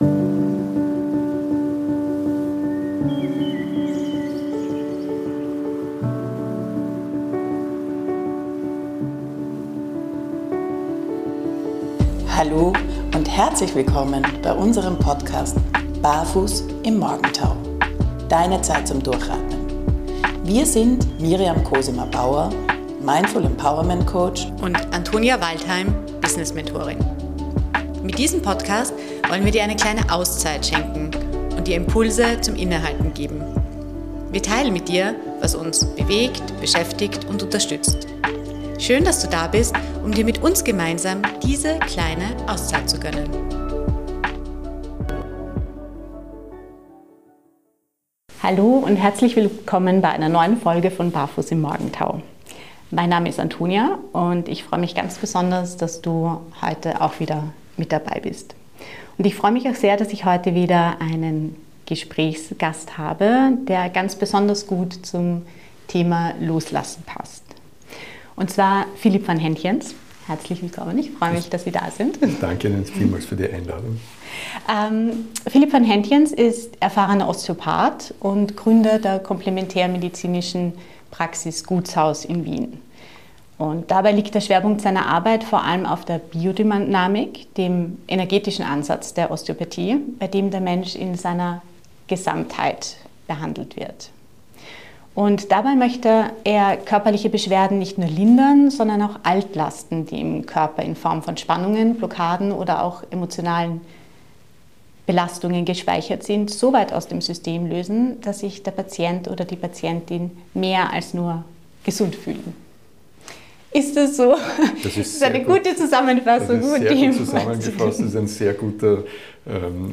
#41 Im Gespräch